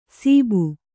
♪ sību